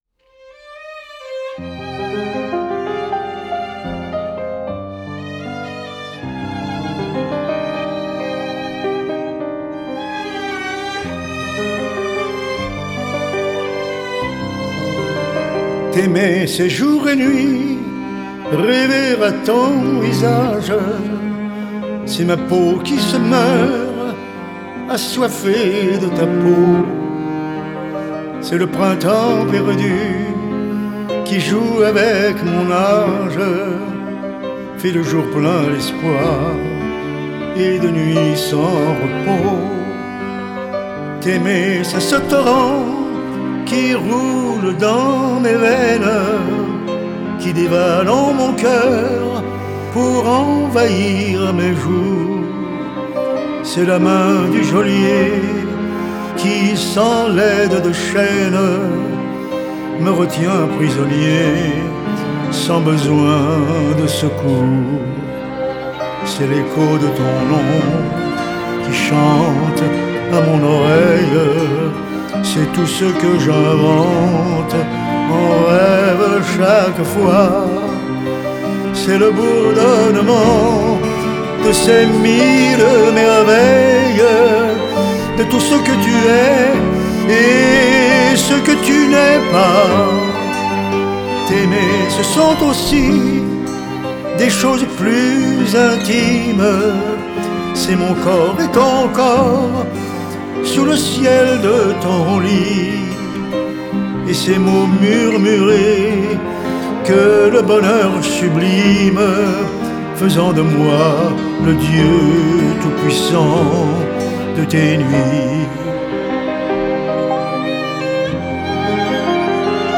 Genre: Chanson